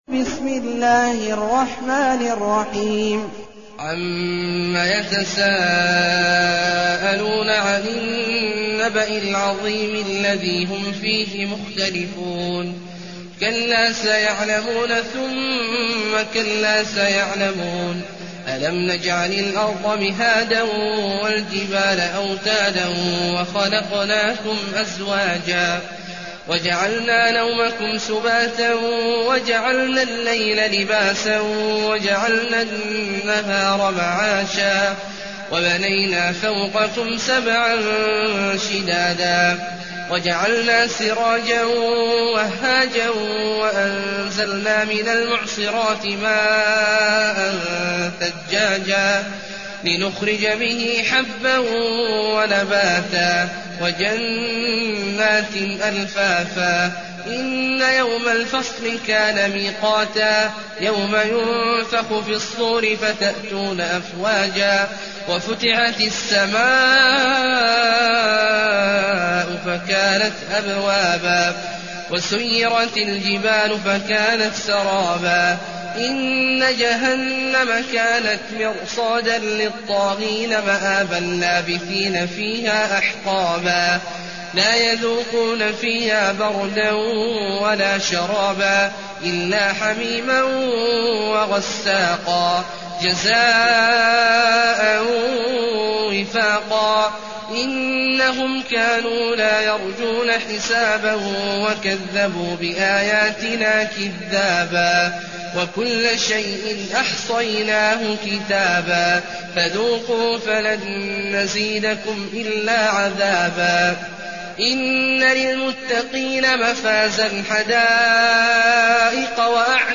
المكان: المسجد النبوي الشيخ: فضيلة الشيخ عبدالله الجهني فضيلة الشيخ عبدالله الجهني النبأ